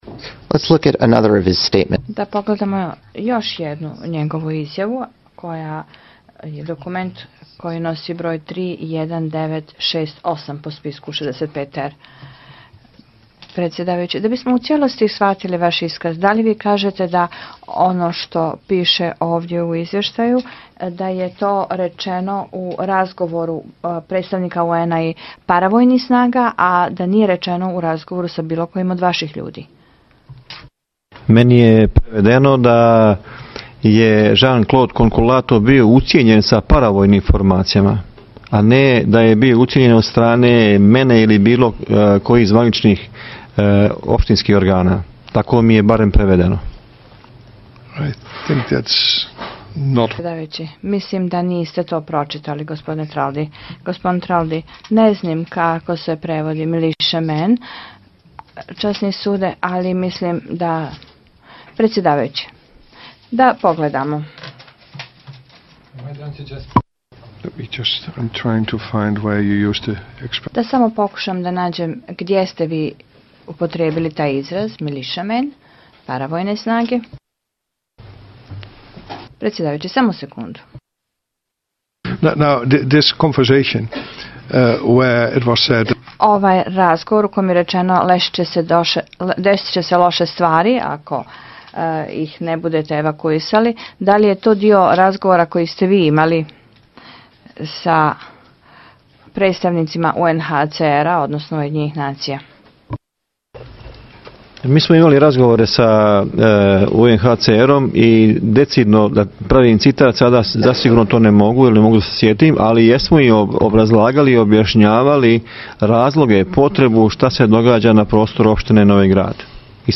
Dio unakrsnog ispitivanja i suočavanje Pašića s prijetnjama njegovih vlasti